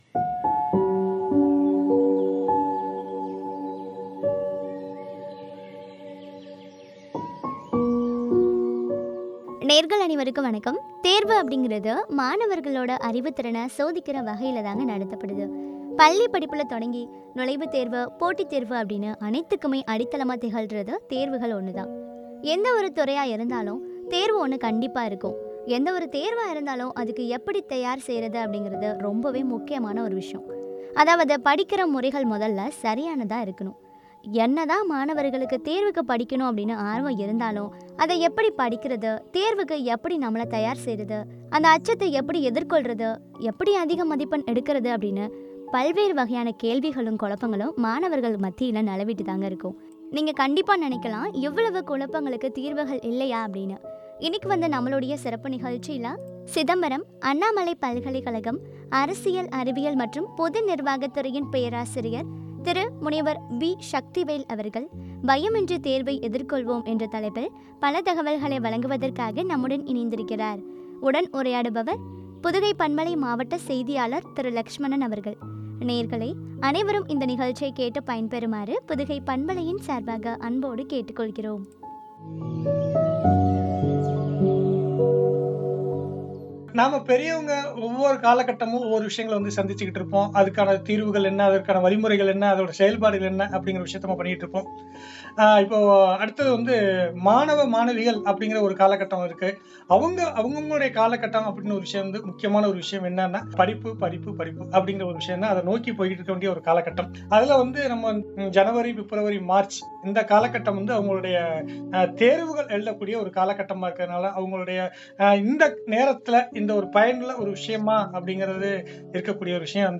பயமின்றி தேர்வை எதிர்கொள்வோம் என்ற தலைப்பில் வழங்கிய உரையாடல்.